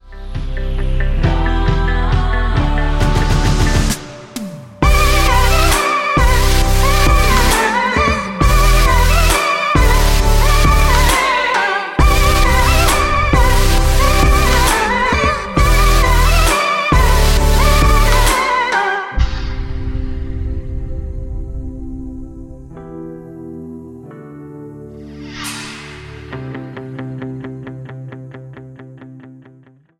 Em
Backing track Karaoke
Pop, 2010s